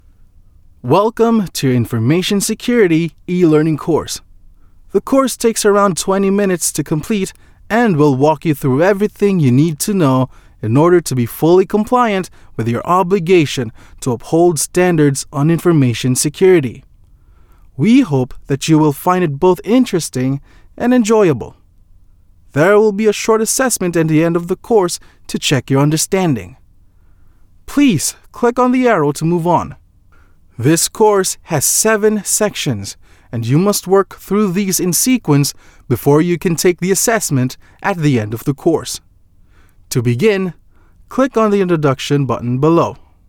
PH ENGLISH MALE VOICES